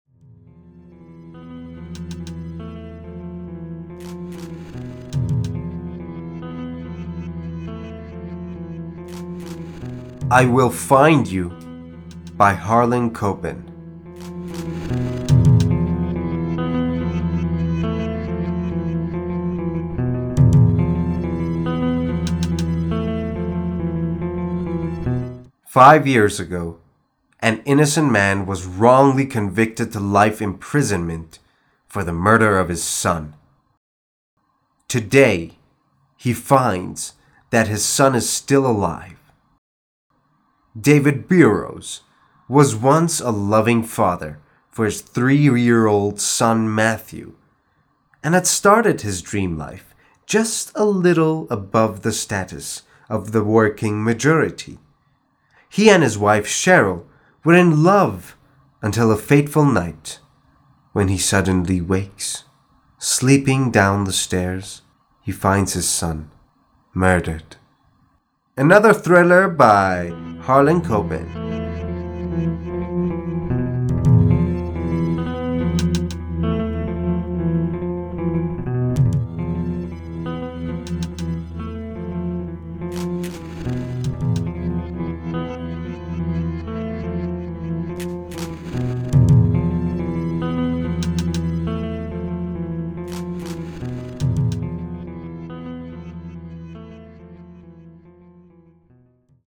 معرفی صوتی کتاب I Will Find You